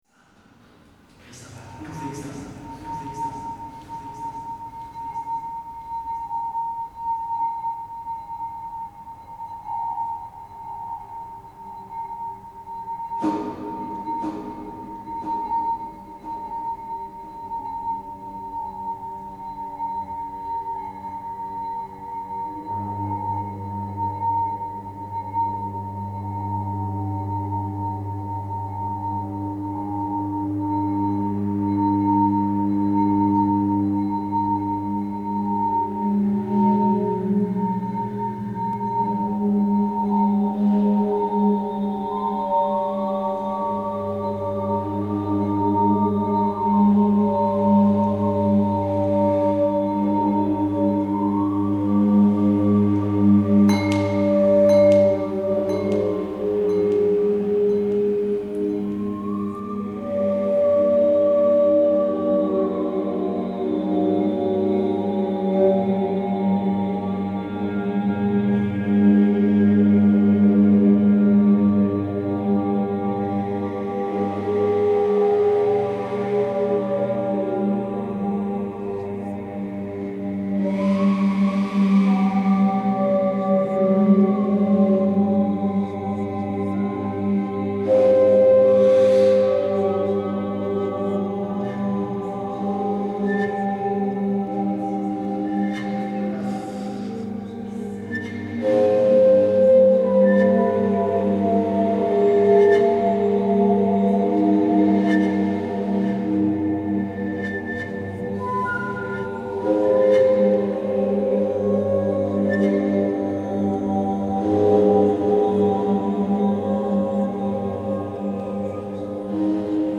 Overtone singing